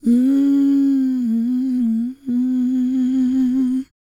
E-CROON P317.wav